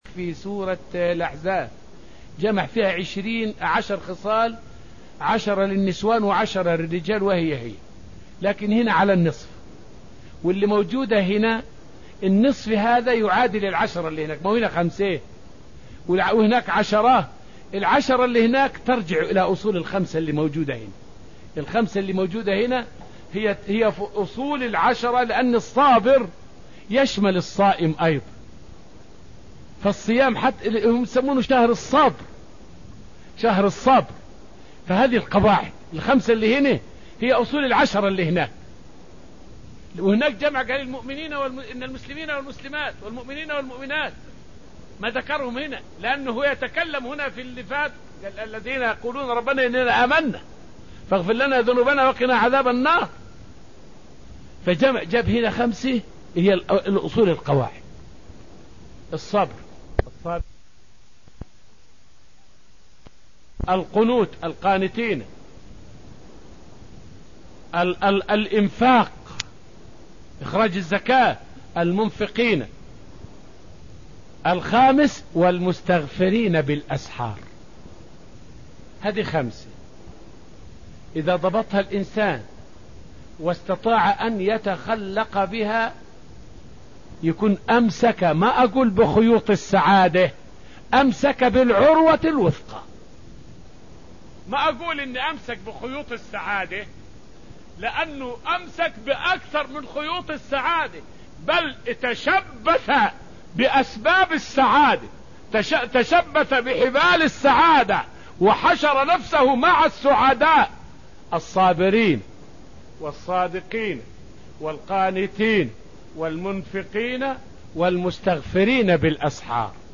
فائدة من الدرس الخامس من دروس تفسير سورة الأنفال والتي ألقيت في رحاب المسجد النبوي حول الإيمان بالله وأنه أول مراتب السعادة.